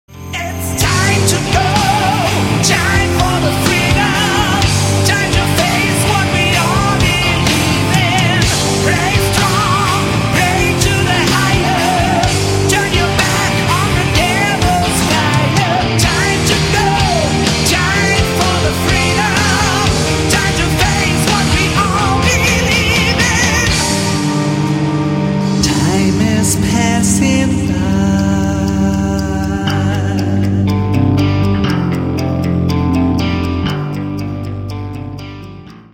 Metal
heavy Metal